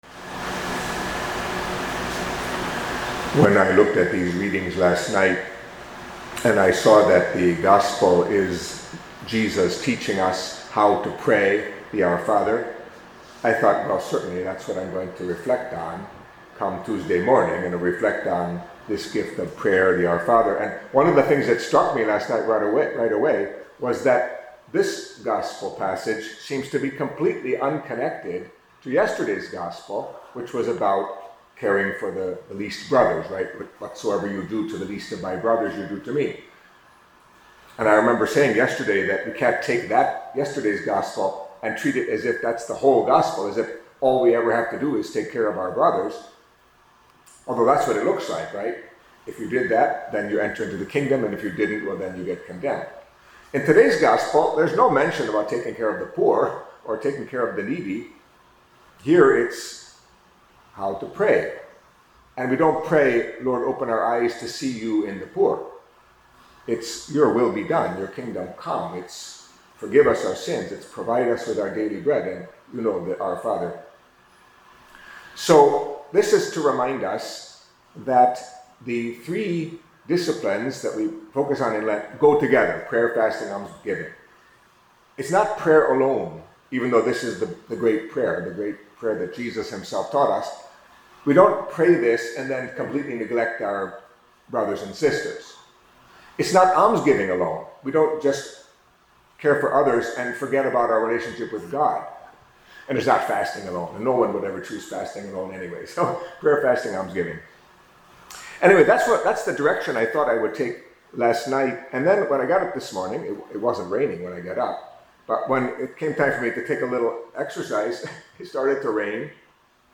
Catholic Mass homily for Tuesday of the First Week of Lent